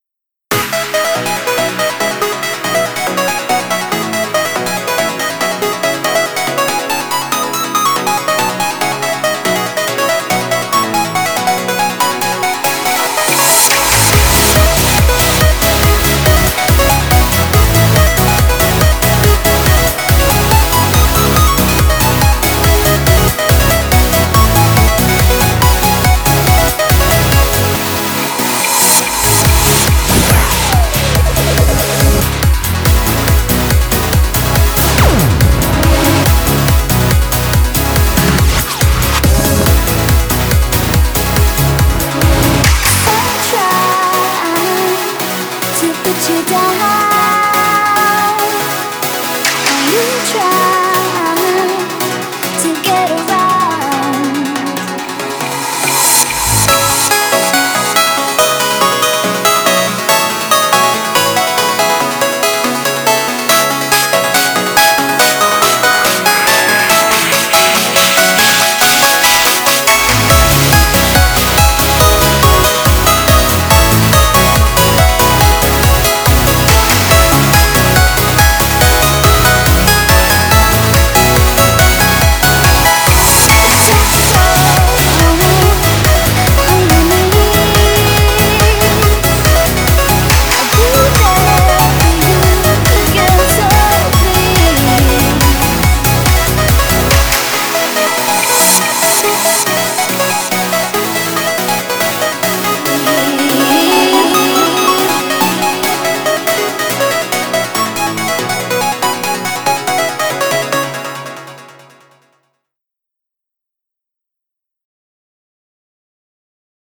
BPM141
Audio QualityPerfect (High Quality)